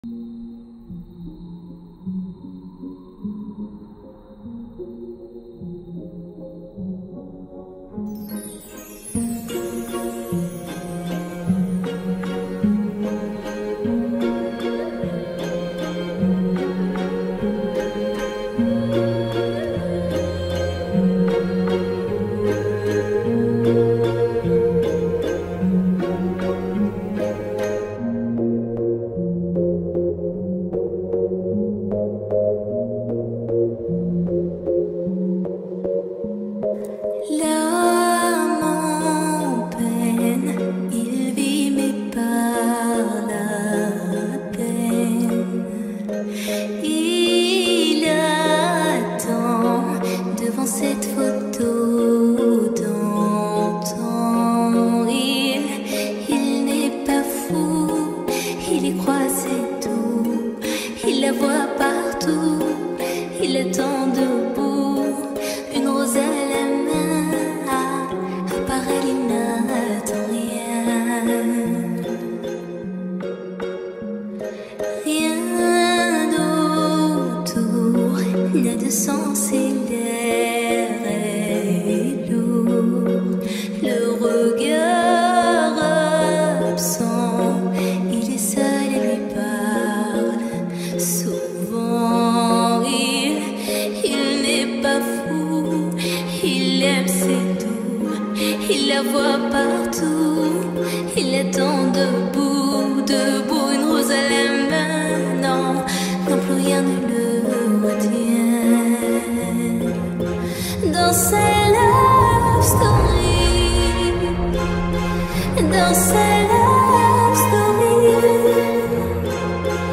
با ریتمی کند شده